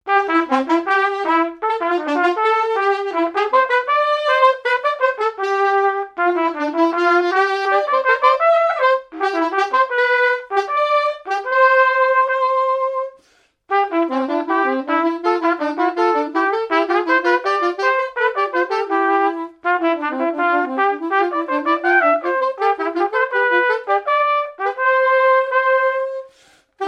Marche nuptiale
répertoire de marches de noces
Pièce musicale inédite